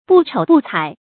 不瞅不睬 bù chǒu bù cǎi
不瞅不睬发音
成语注音ㄅㄨˋ ㄔㄡˇ ㄅㄨˋ ㄘㄞˇ